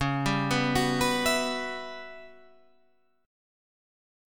Listen to Em6add9/C# strummed